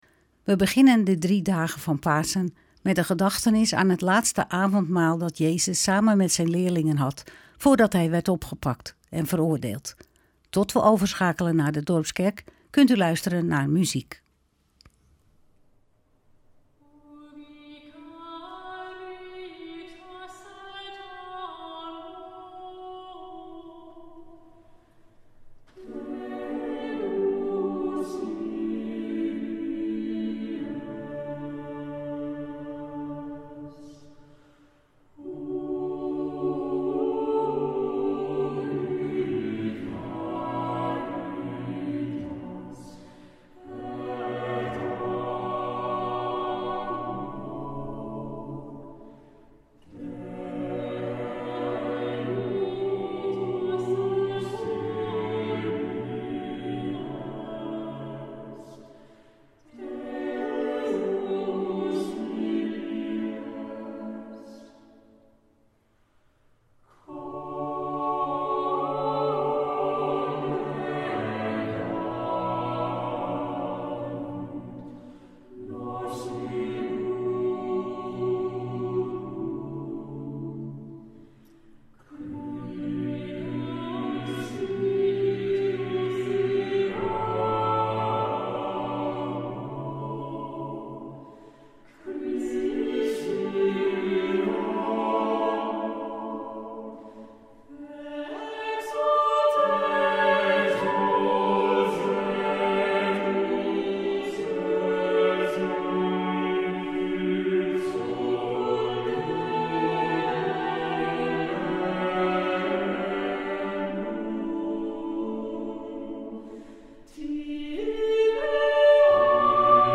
Opening van deze Witte Donderdag met muziek, rechtstreeks vanuit onze studio.